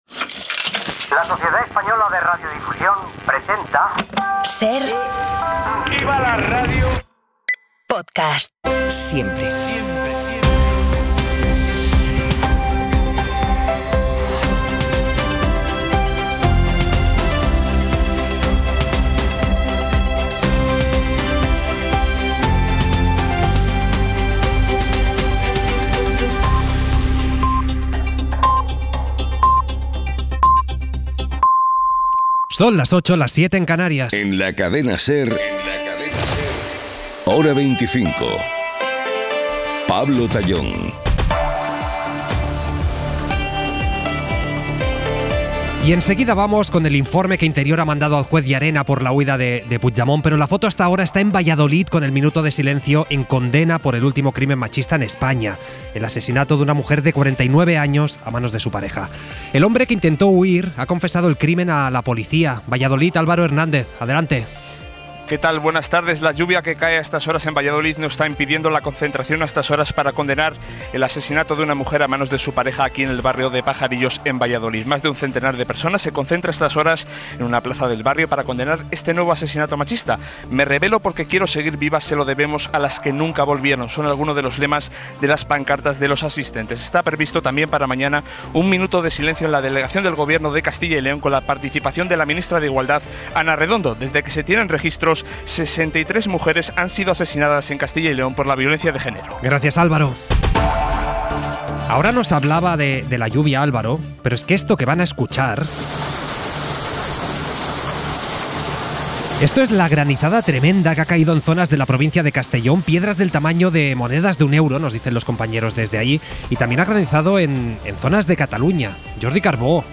Valladolid celebra un minuto de silencio para condenar el último crimen machista en España 29:10 SER Podcast Las noticias de la tarde